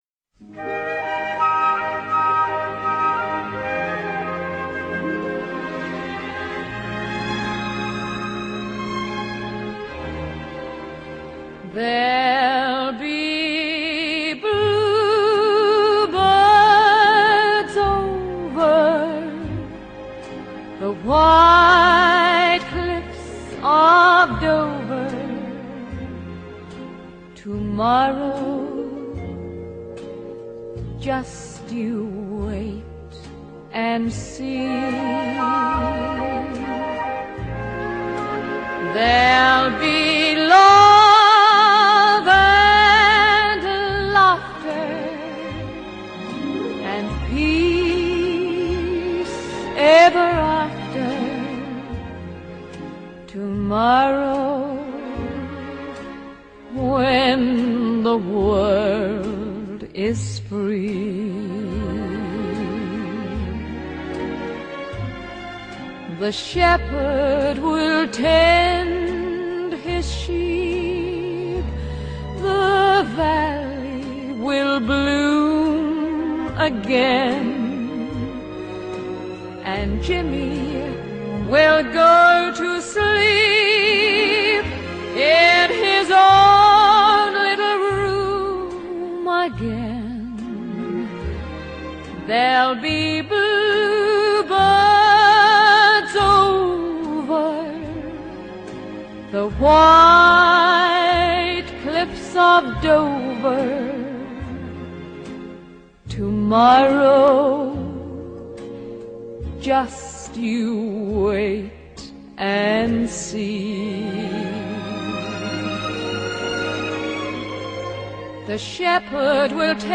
Сентиментальная музыка из иного времени...